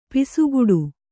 ♪ pisuguḍu